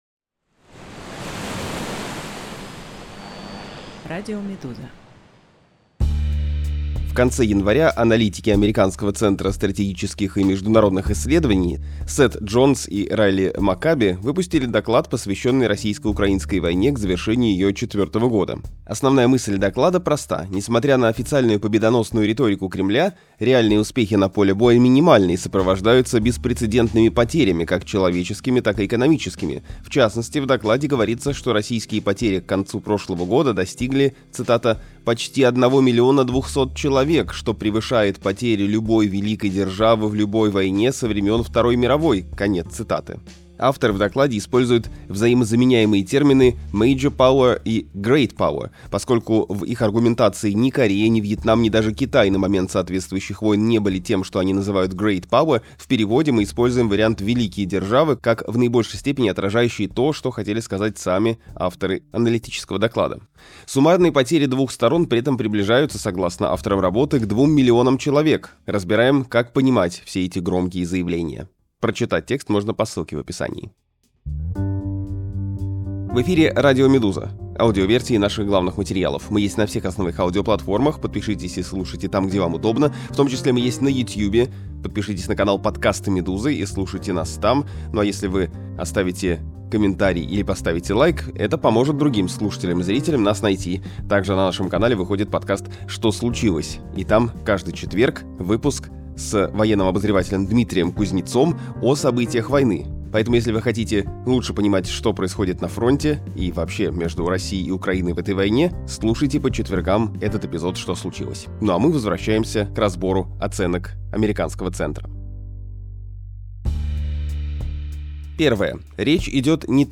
Аудиоверсия разбора «Медузы».